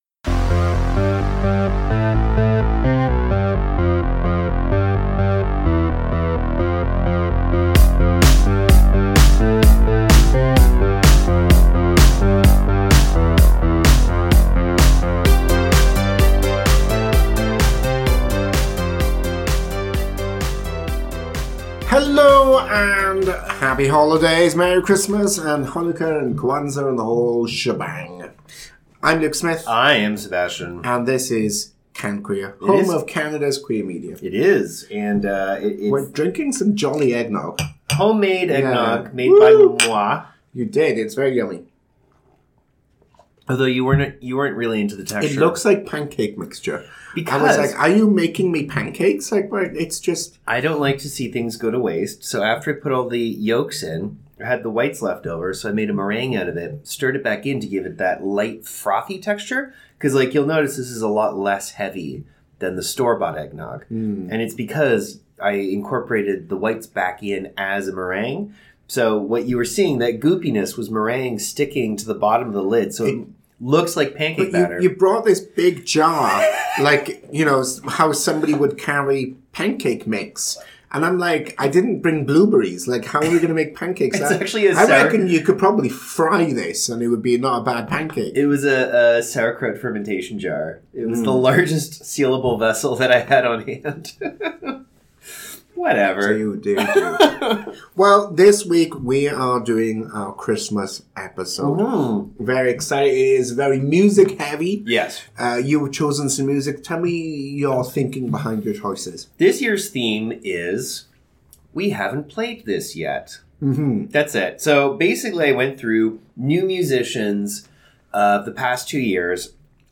A music-oriented episode featuring new and upcoming LGBTQ musicians.